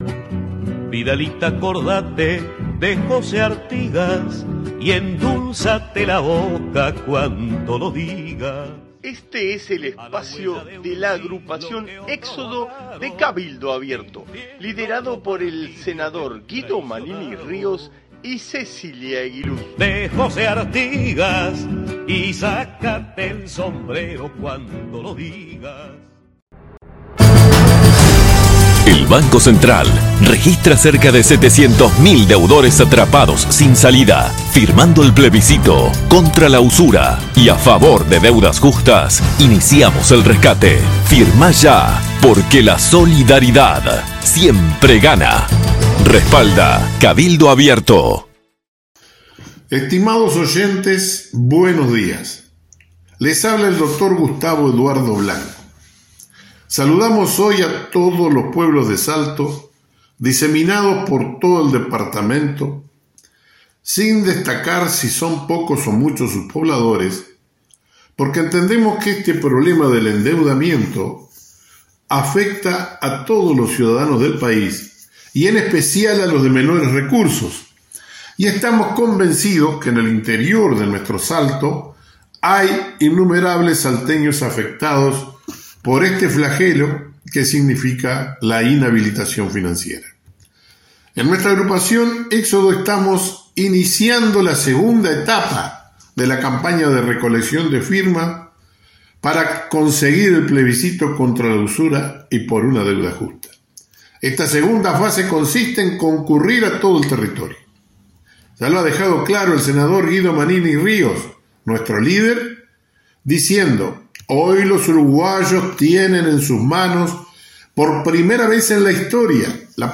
Audición radial de nuestra agrupación para Radio Salto(1120AM) del día 31 de octubre de 2023.